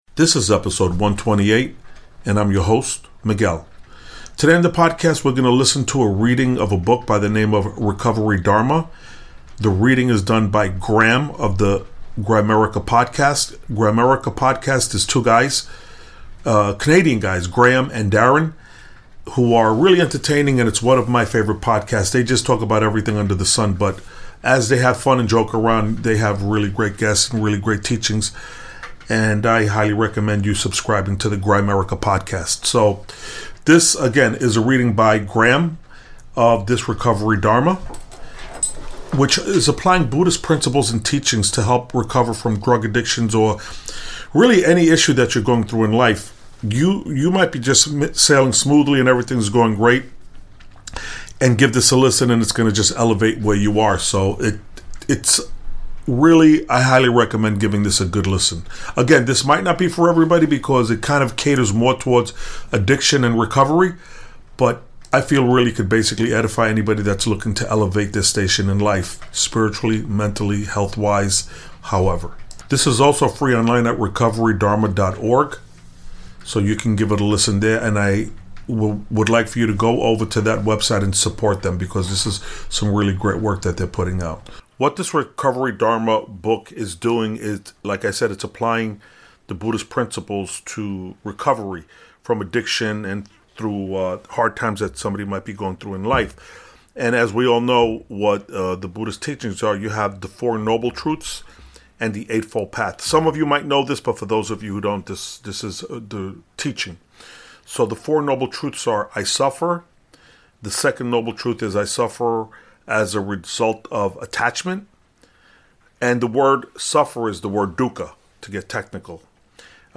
EP 128 PART 1 - RECOVERY DHARMA AUDIO BOOK - BUDDHIST TEACHINGS GIVING TO POWER TO RECOVER FROM ADDICTION